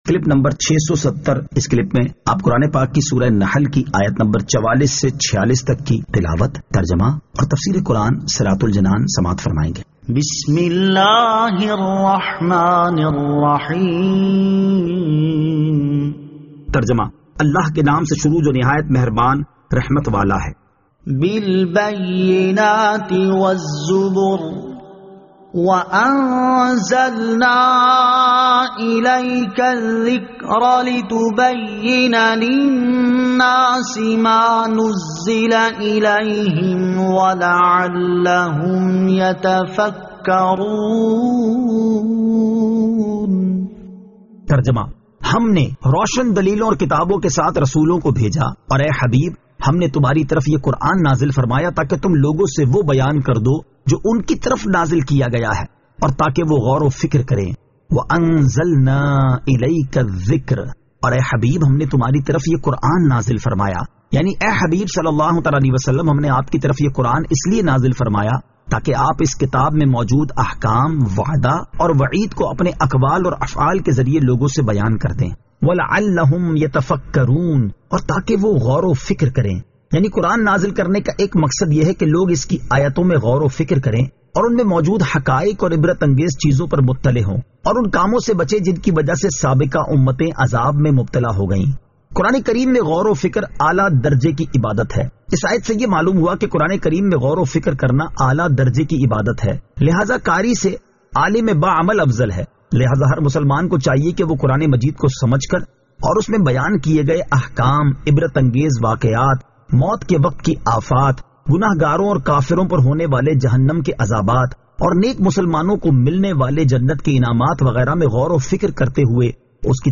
Surah An-Nahl Ayat 44 To 46 Tilawat , Tarjama , Tafseer